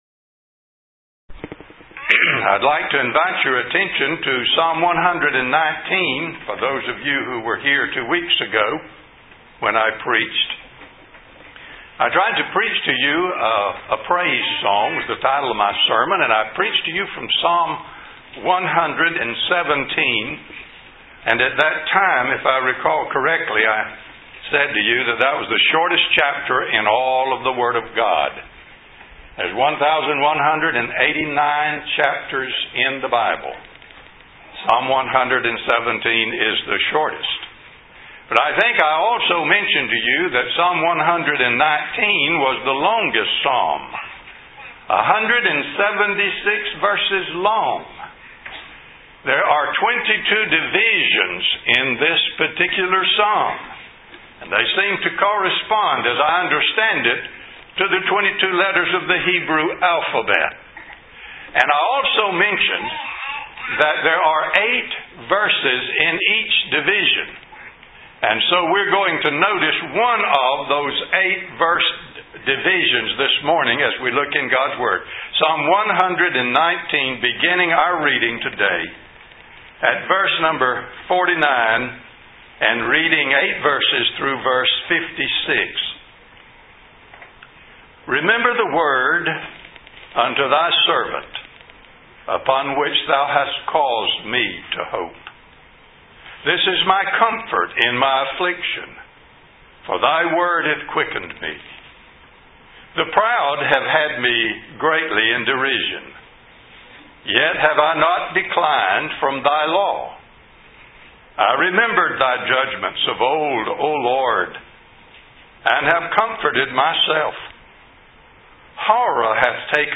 Sermon by Speaker